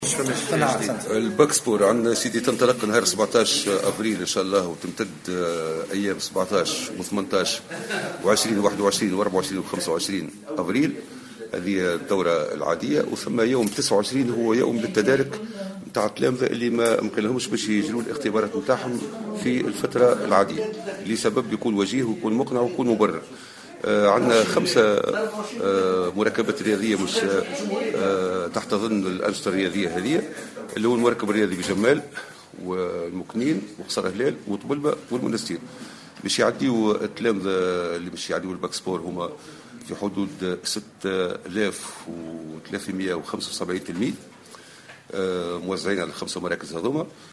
سيجتاز 6375 تلميذا في ولاية المنستير اختبار التربية البدينة، خلال الفترة من 17 إلى 29 أفريل 2017، في 5 مركبات الرياضية بكلّ من "قصر هلال" و"المكنين" و"طبلبة" و"جمال"، بحسب ما صرح به لمراسل الجوهرة أف أم، المندوب الجهوي للتربية بالمنستير، رضا عمارة.